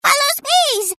Tags: Everquest 2 Ratonga emote Heals me I dont think soes